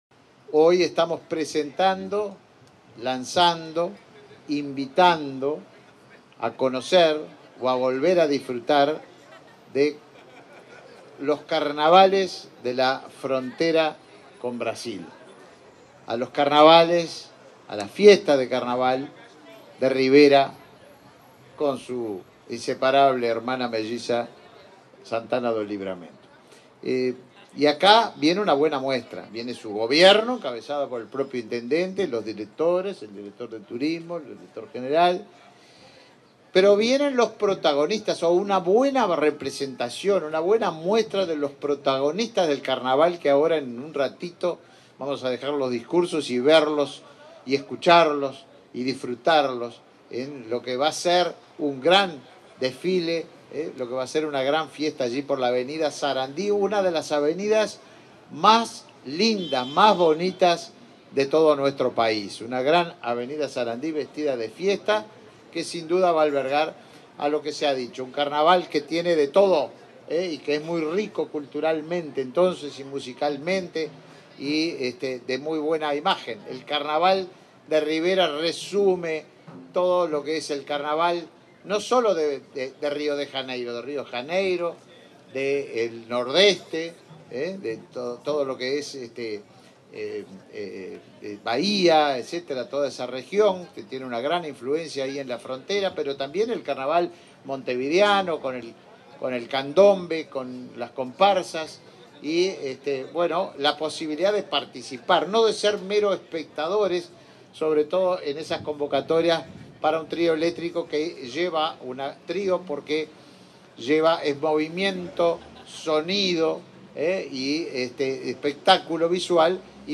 Palabras del ministro de Turismo, Tabaré Viera
En el marco del lanzamiento del Carnaval de Rivera, este 5 de febrero, se expresó el ministro de Turismo, Tabaré Viera.